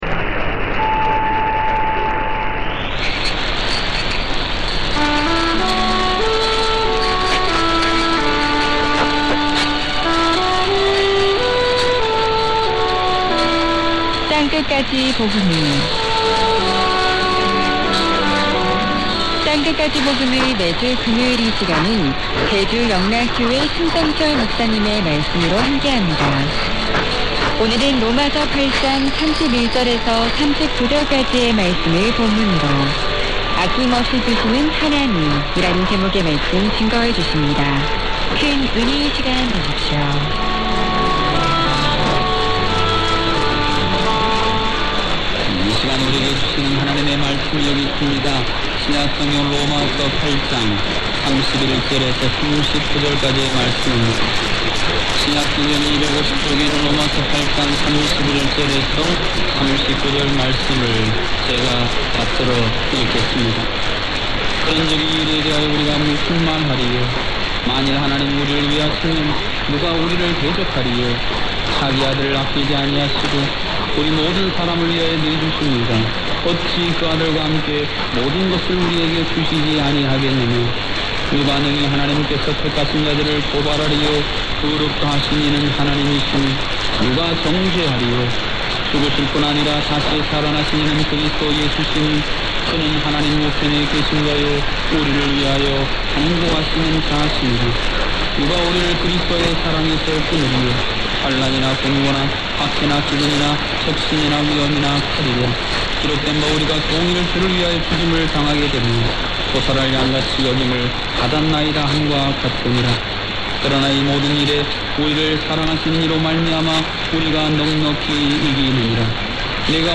- az egész órás "sípot" már a 1566 kHz-en vehető dél-koreai adóról rögzítettem: